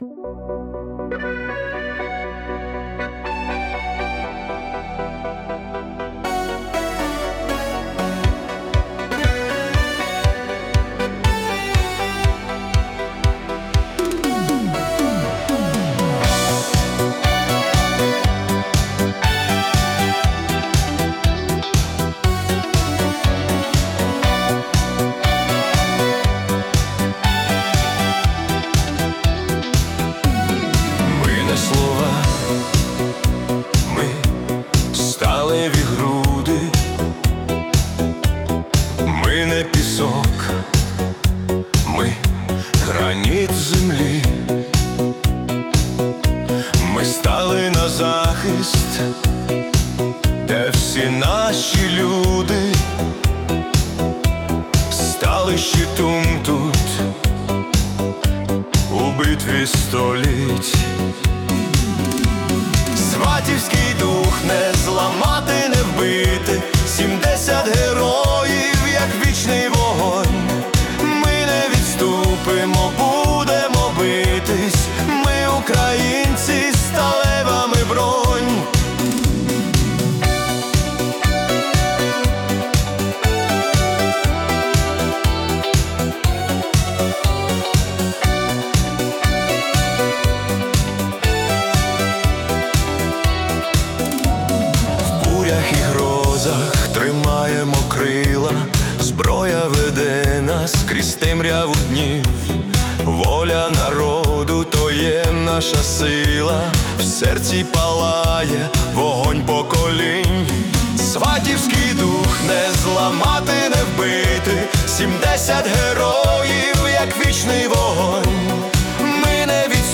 🎵 Жанр: Patriotic Italo Disco